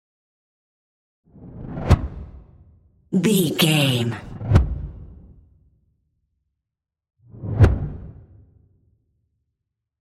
Whoosh flap x3
Sound Effects
dark
tension
whoosh